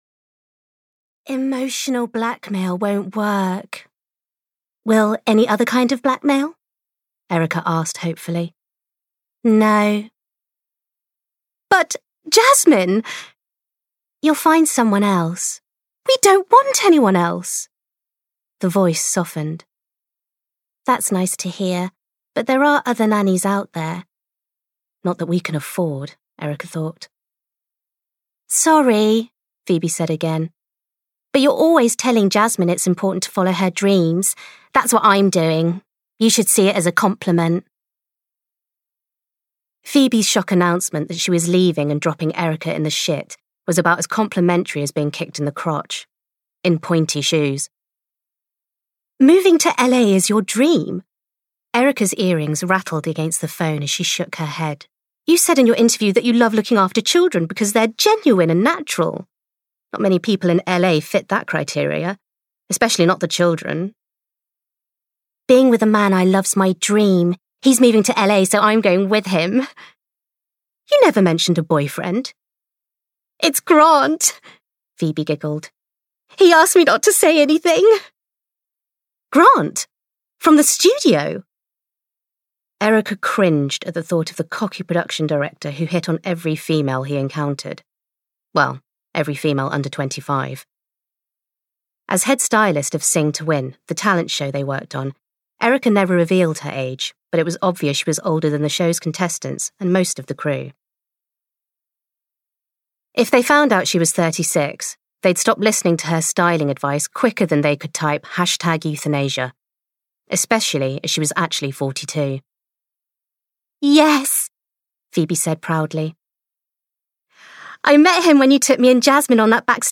Wife Support System (EN) audiokniha
Ukázka z knihy